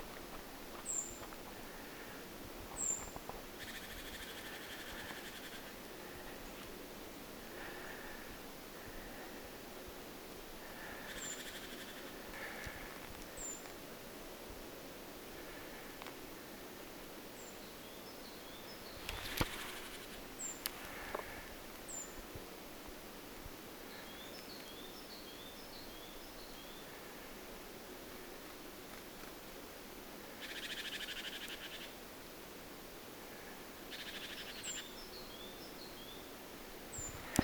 ti-puukiipijä,
talitiainen laulaa titityy
ti-puukiipija_talitiainen_laulaa_titityy.mp3